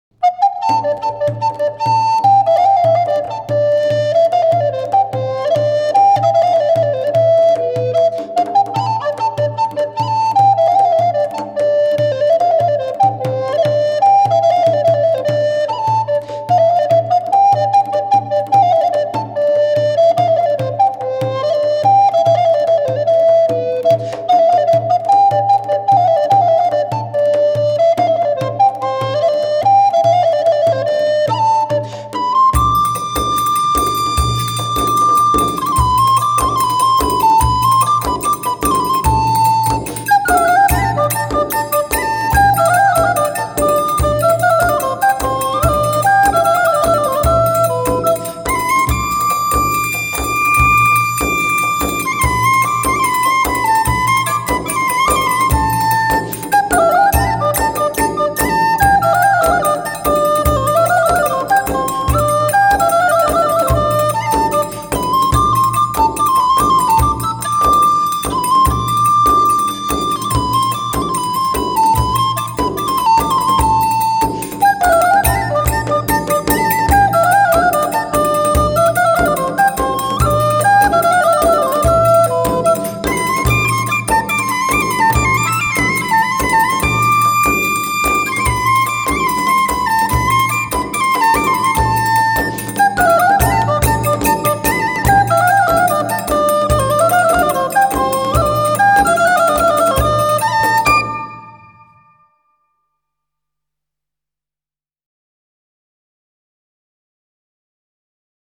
Saltarellos
Danza
Música tradicional